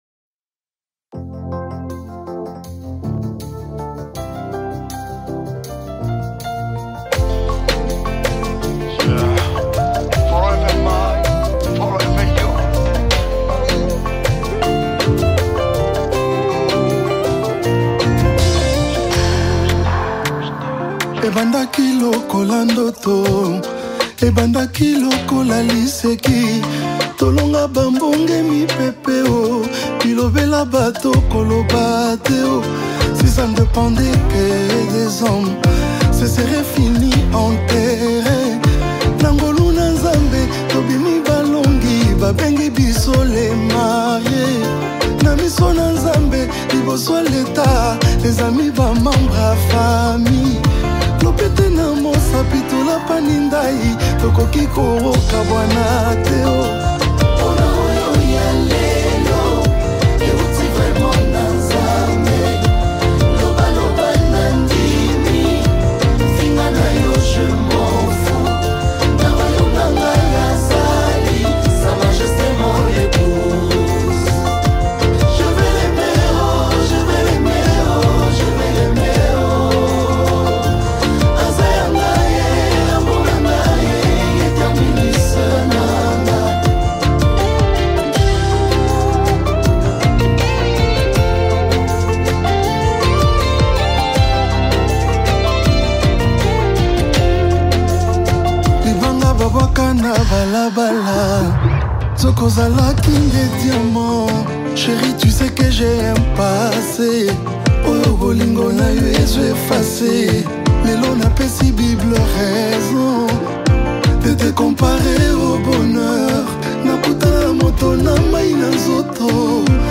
Congo Gospel Music
touching and faith-inspired song
a voice of passion and authenticity